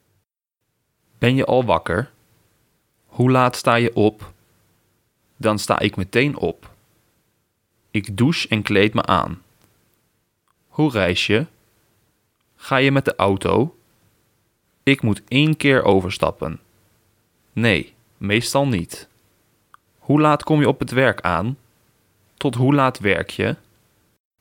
Przesłuchaj zdania wypowiedziane przez holenderskiego native speakera i przekonaj się sam!